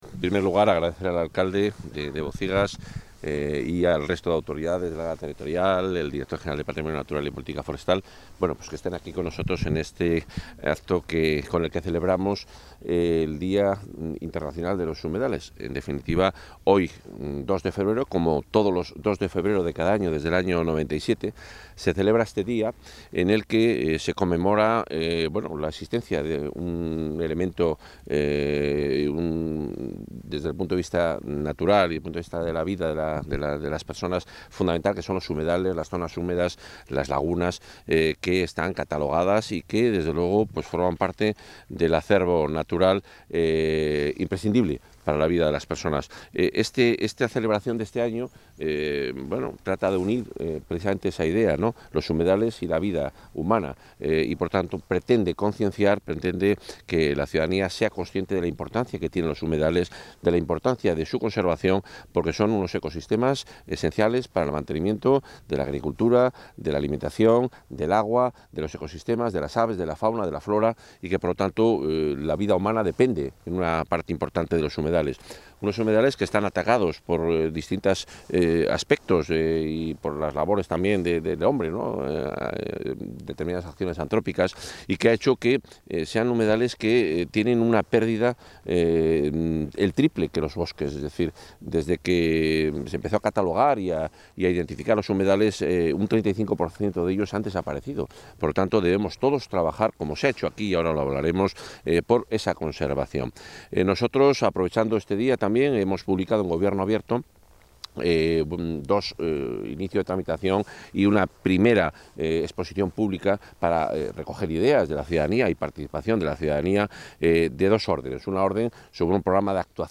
Intervención del consejero.
Este año, con motivo de la celebración del Día Mundial de los Humedales, el consejero de Medio Ambiente, Vivienda y Ordenación del Territorio, Juan Carlos Suárez-Quiñones, ha visitado una de las zonas húmedas más interesantes de la provincia de Valladolid, el Bodón Blanco (Bocigas).